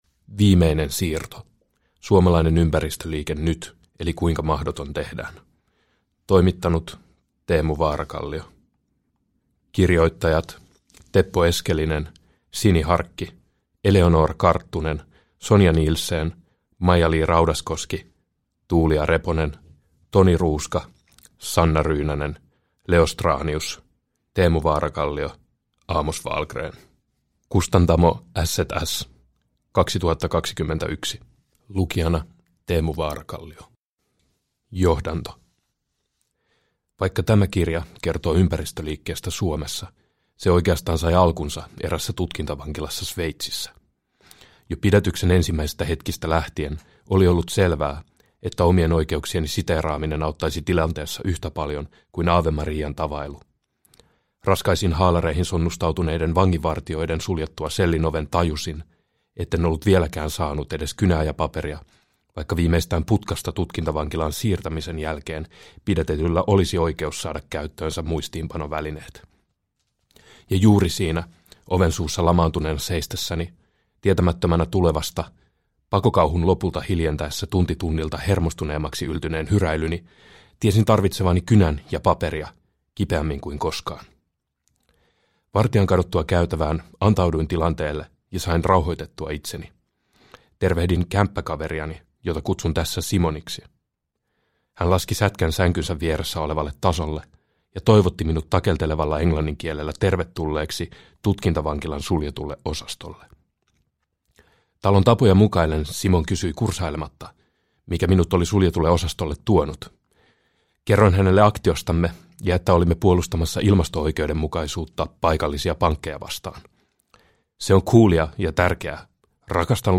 Viimeinen siirto – Ljudbok – Laddas ner